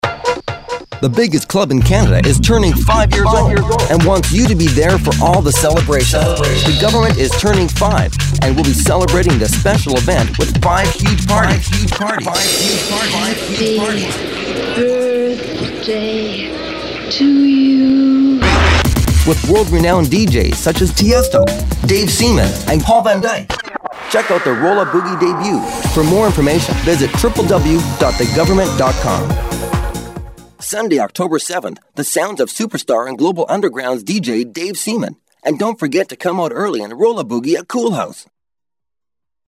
Voice Overs